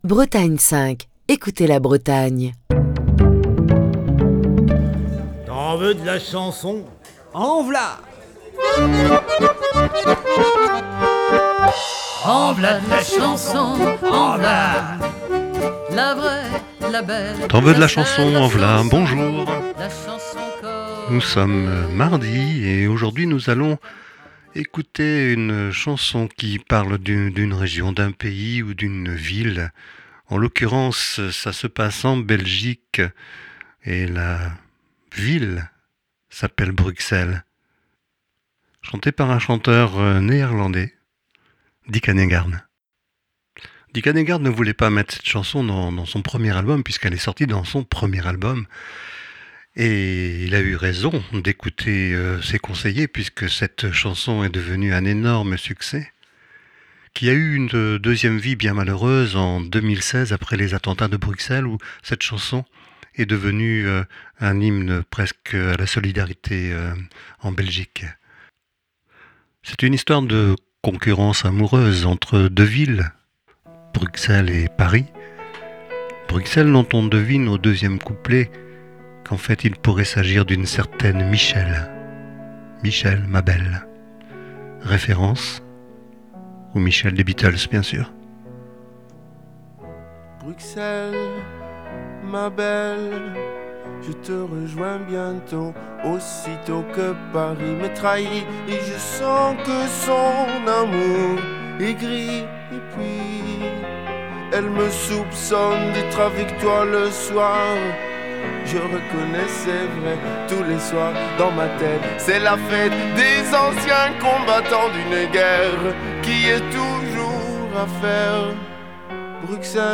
Chronique du 13 juin 2023.